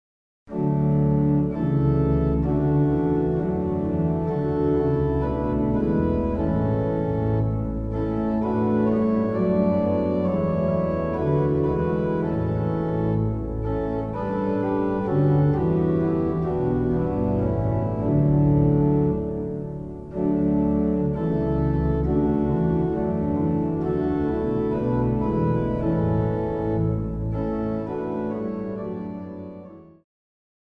Die Notenausgabe für Orgel
Choralsatz